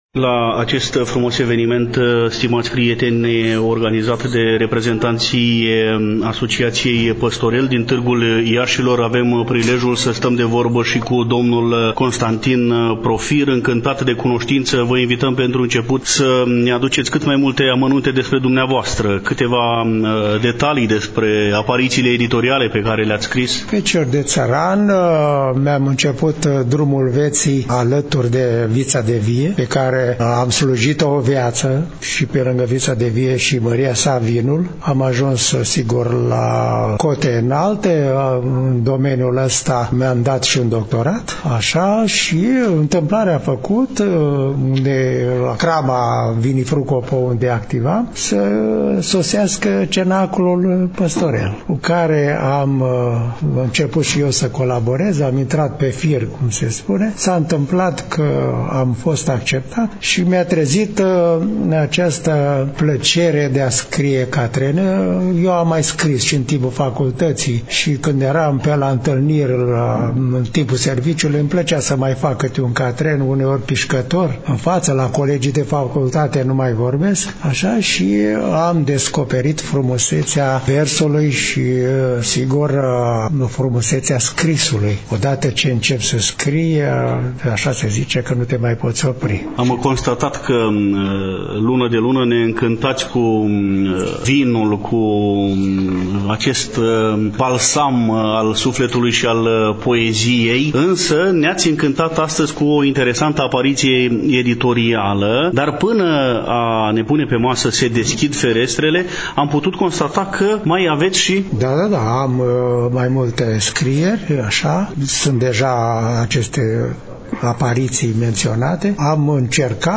Stimați prieteni, o altă voce pe care am înregistrat-o, duminică, 13 august, la ședința lunară a Asociației Literare „Păstorel”